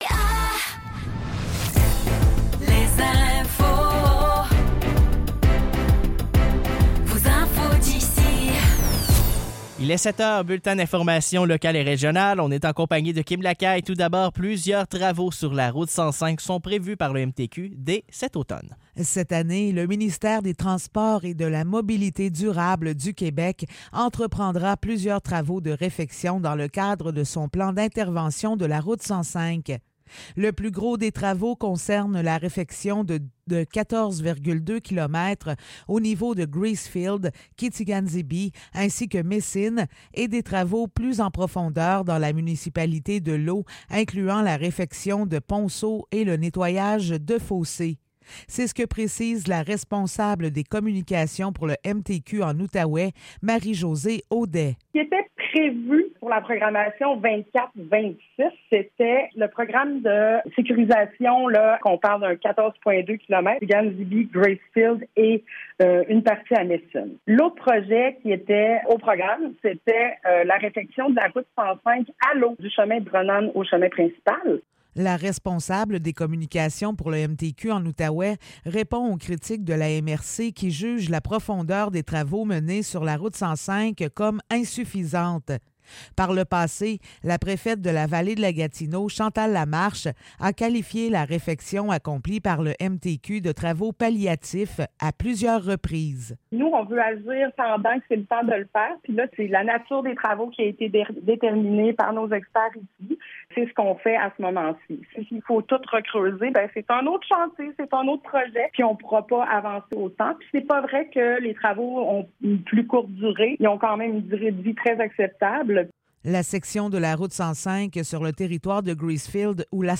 Nouvelles locales - 9 juillet 2024 - 7 h